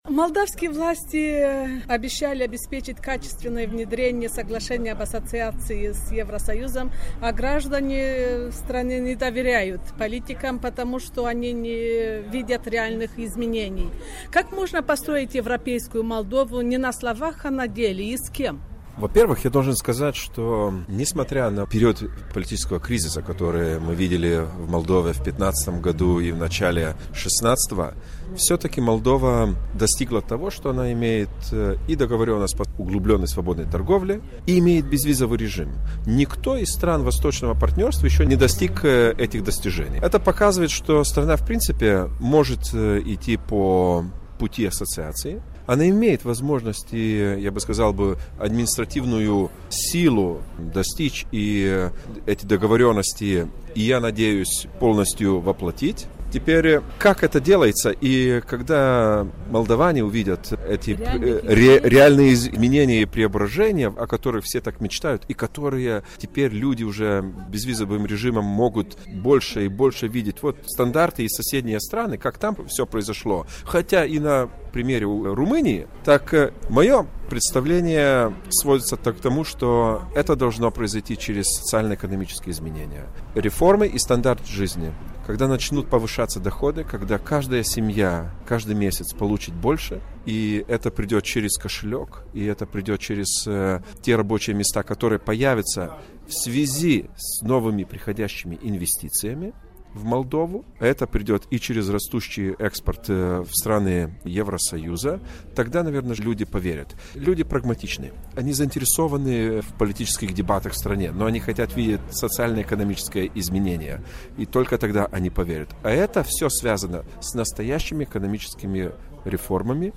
Interviu cu Petras Auštrevičius